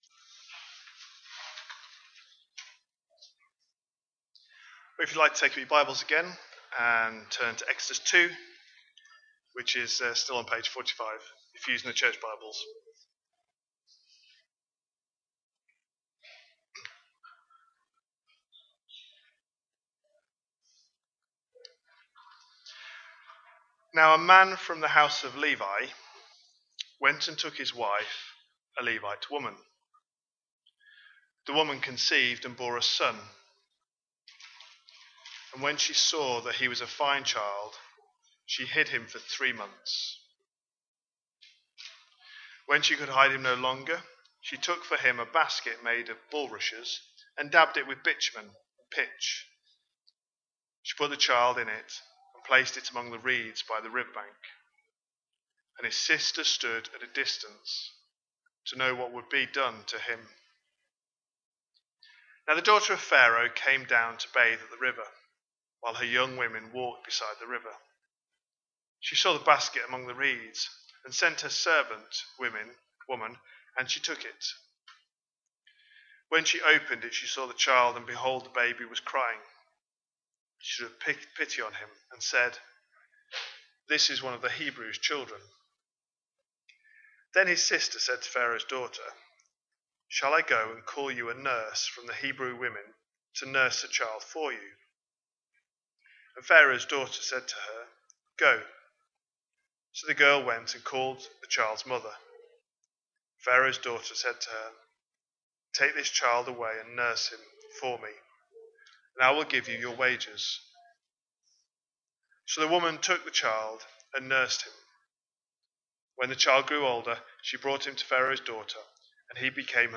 A sermon preached on 13th July, 2025, as part of our Exodus series.